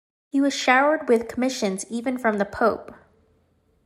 Pronounced as (IPA) /kəˈmɪʃənz/